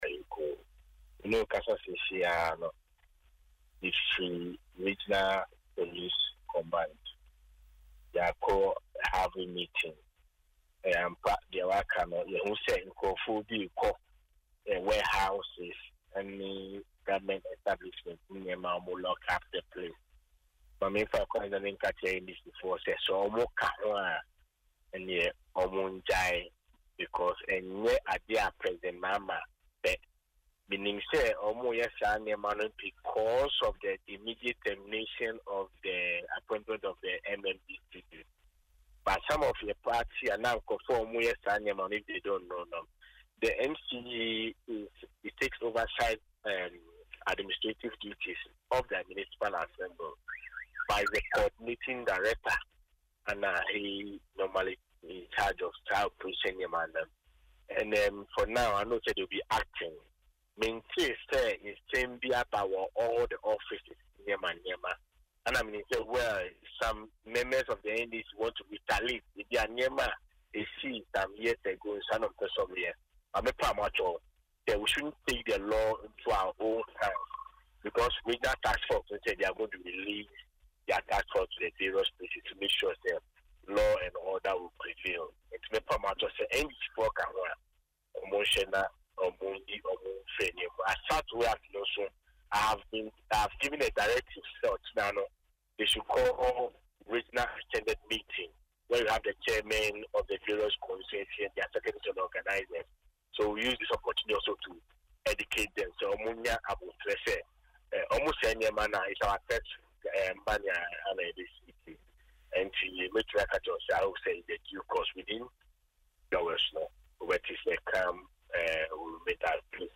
In an interview on Asempa FM’s Ekosii Sen, the former Adentan MP stated that he can only advise NDC supporters to be vigilant, but not to take the law into their own hands.